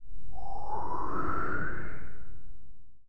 creatures_ghost.1.ogg